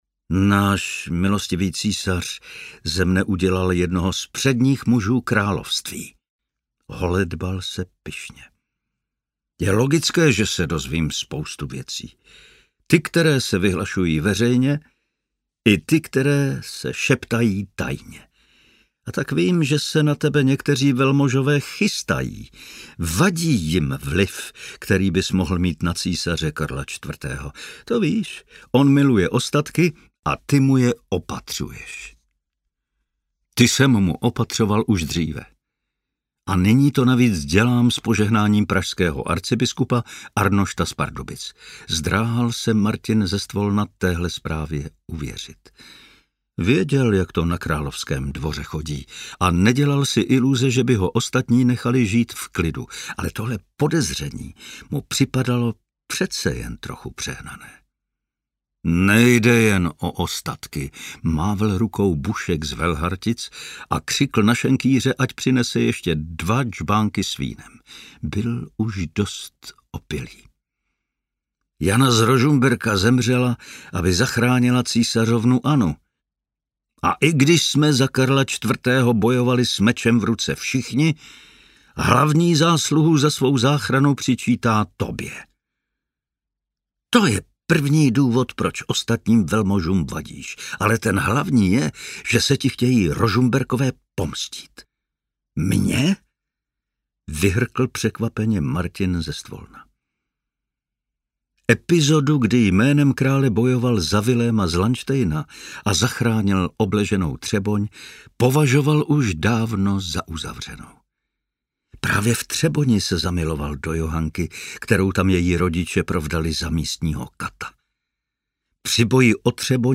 Zloději ostatků I. audiokniha
Ukázka z knihy
• InterpretPavel Soukup